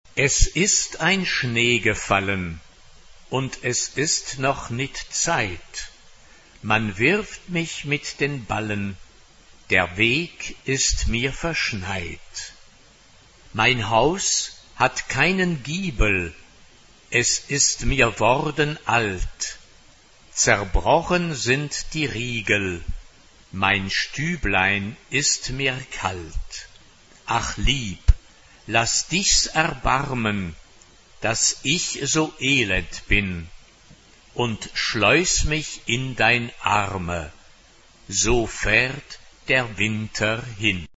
SSAA (4 voices women) ; Full score.
Choir. Renaissance.
Tonality: D major